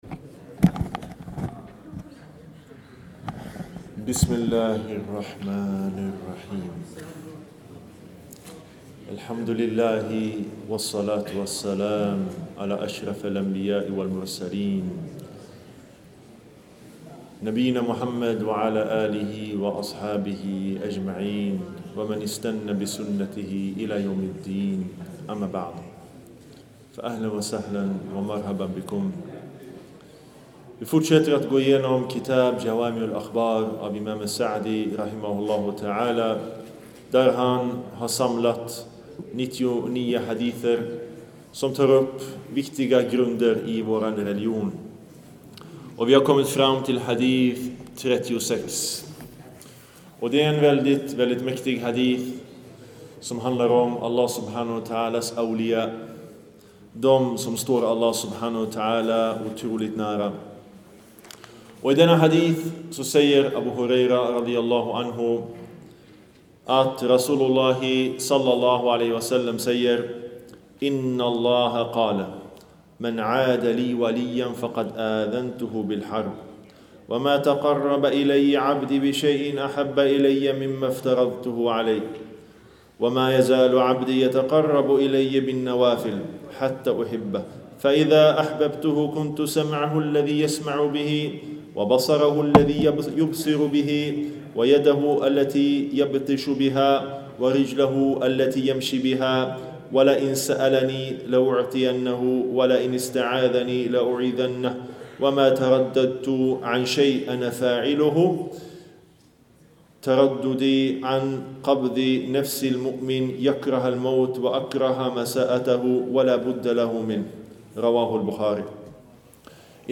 En föreläsning